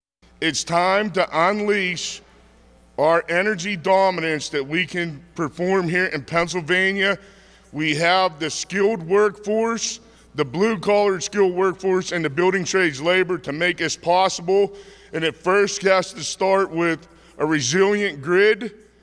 At a news conference yesterday, officials said that the permitting process in Pennsylvania has cost the state many jobs.